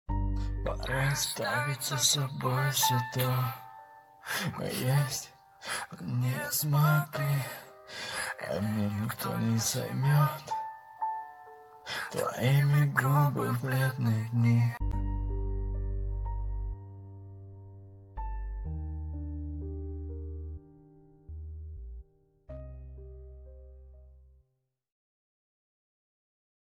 • Качество: 320, Stereo
мужской голос
лирика
грустные